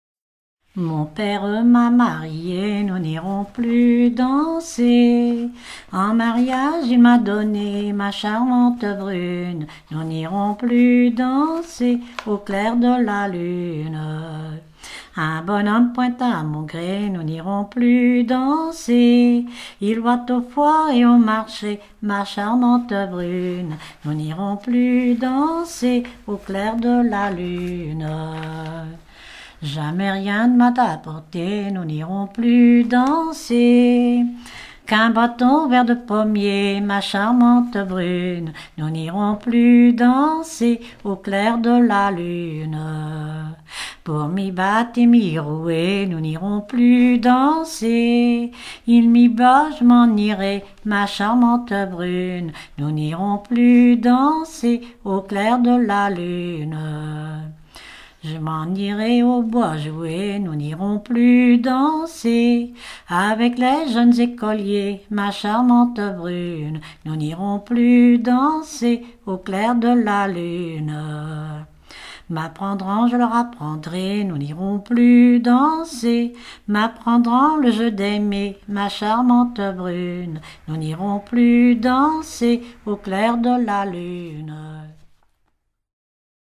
Localisation Dompierre-sur-Yon
Genre laisse